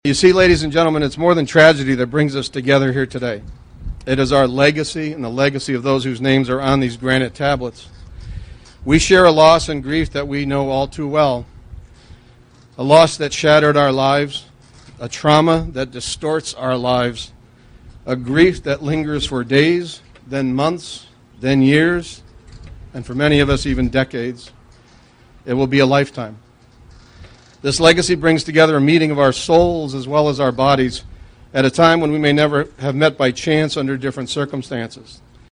Well over 100 people gathered at the National Memorial to Fallen Educators for Friday’s rededication as part of the National Teachers Hall of Fame induction activities.
Several speakers were at the podium.